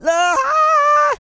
E-CROON 3032.wav